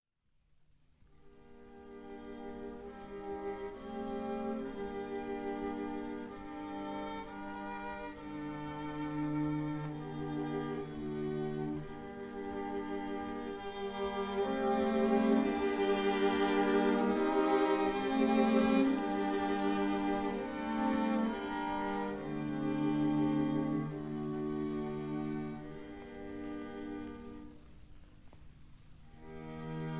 violin
viola